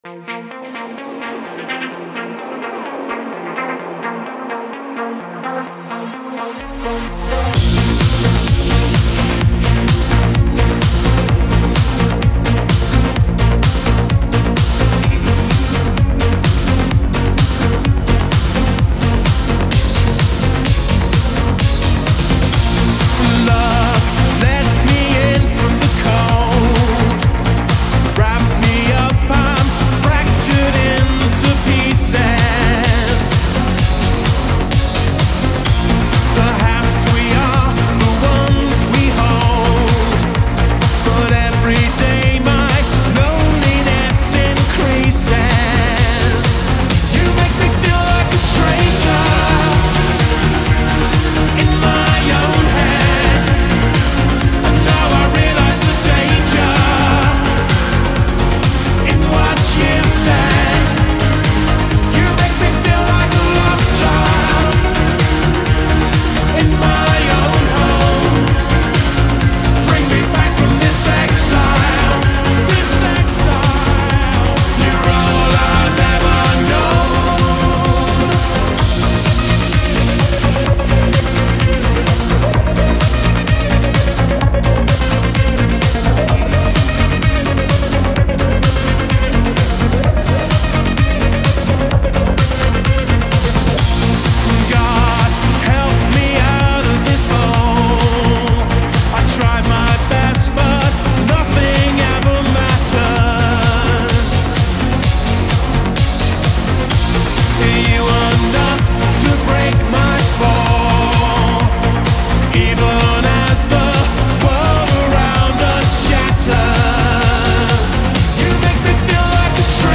leans into texture more than volume